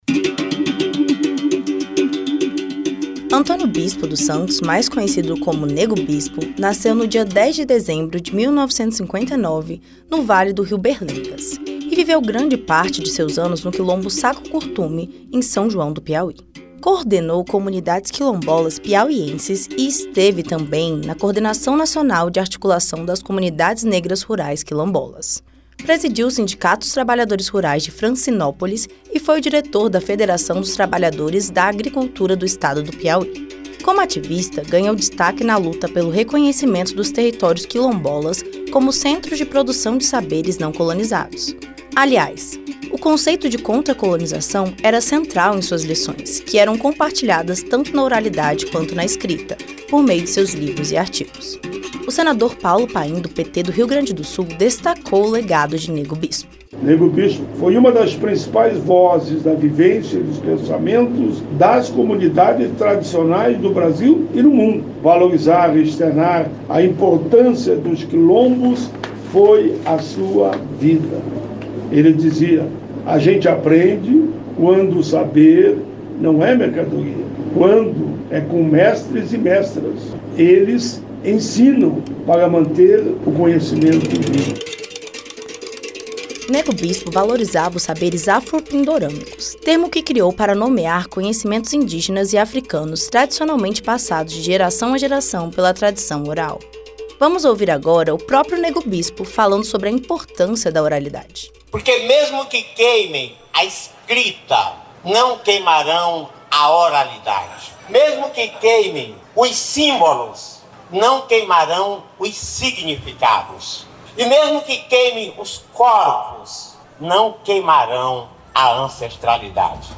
O senador Paulo Paim (PT-RS) fala sobre a importância de Nêgo Bispo na construção de saberes e na luta por direitos das comunidades quilombolas.